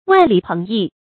万里鹏翼 wàn lǐ péng yì 成语解释 比喻前程远大。